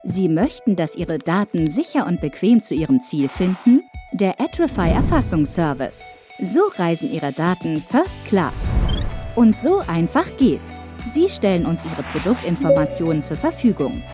Female
Werbung Grover.mp3
Microphone: Neumann TLM103, Rode NT1 A